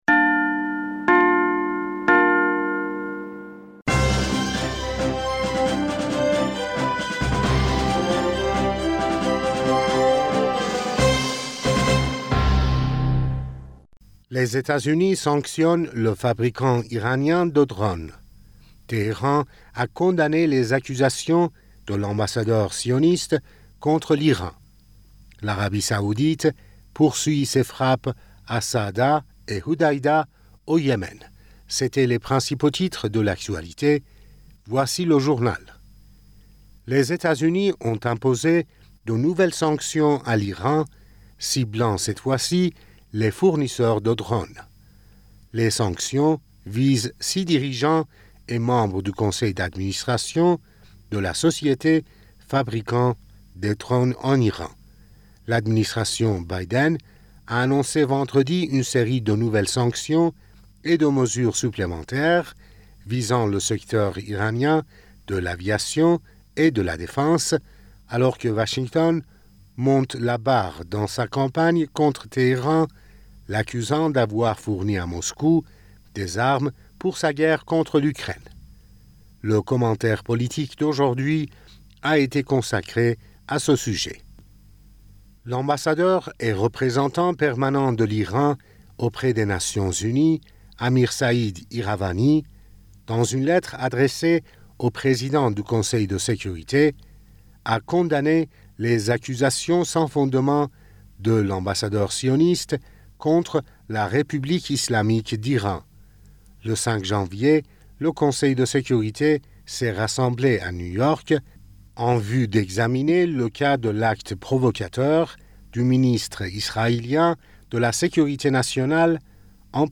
Bulletin d'information du 07 Janvier